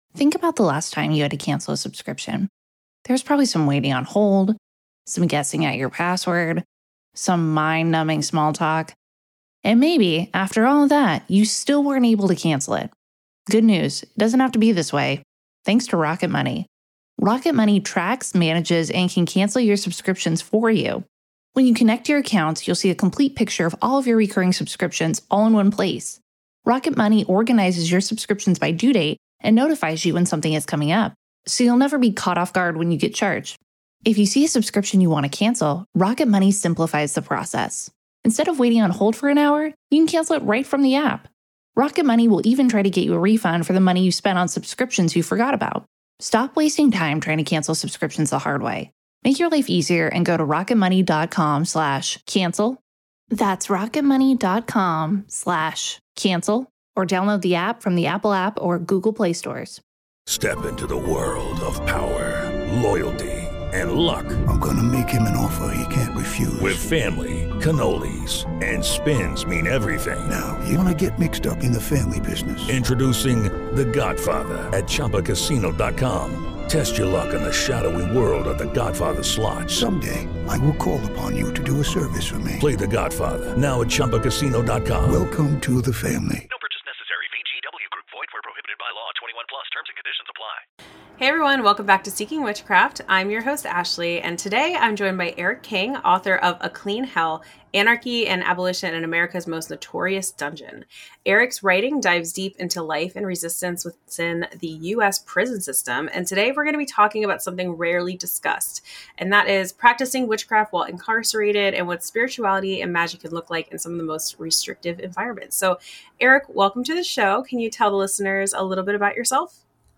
This episode offers a conversation about survival, resistance, and the magic we create even in the darkest places.